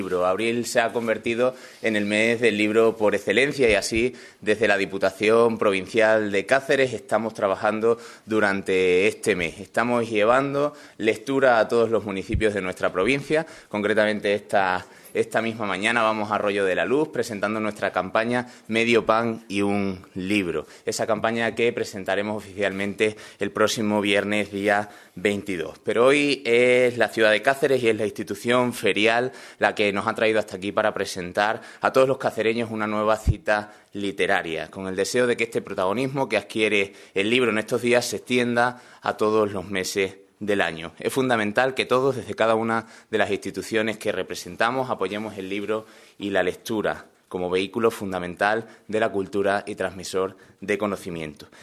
CORTES DE VOZ
18/04/2016, Cáceres.- El Diputado de Cultura, Juventud y Deportes, Álvaro Sánchez Cotrina, ha participado en la presentación de la XVIII Edición de la Feria del Libro que organiza IFECA, Institución Ferial de Cáceres.